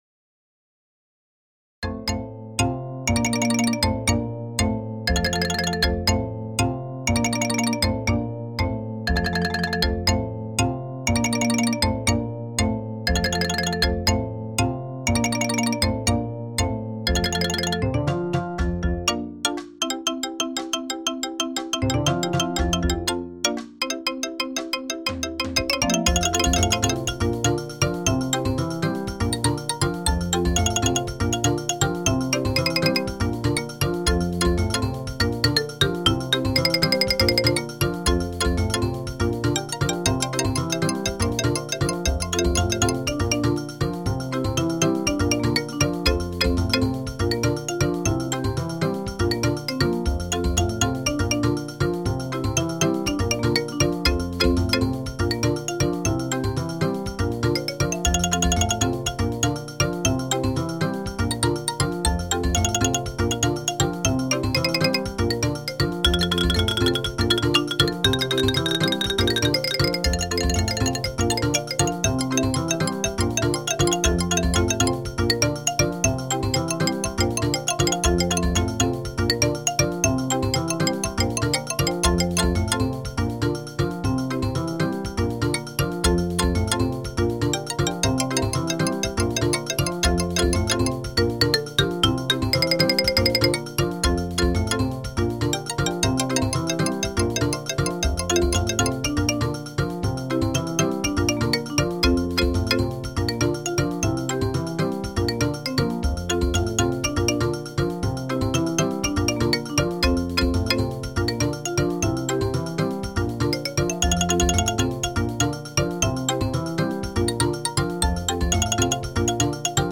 Mallet-Steelband
Bells Xylofoon Marimba Bass Drumset A Gogo Bells